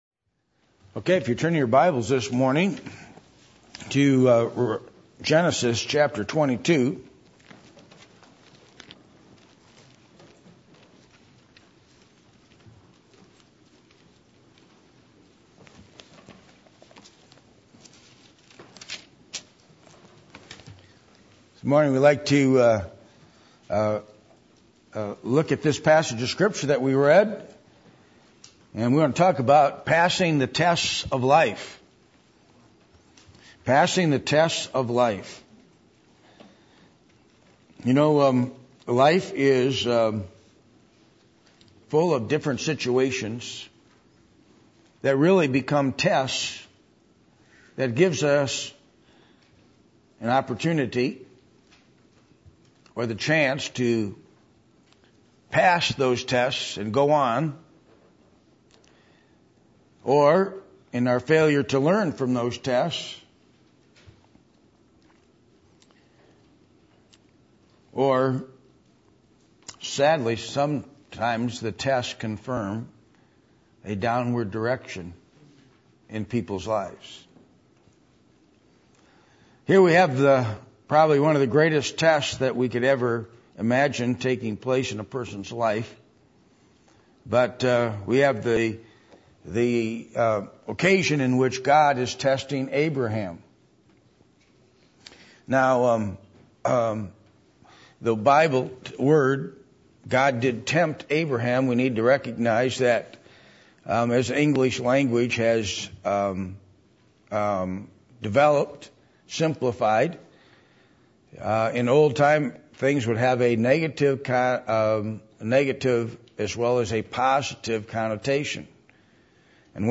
Genesis 22:1-14 Service Type: Sunday Morning %todo_render% « Decisions To Choose Righteousness Are You A Blender In…Or A Contender?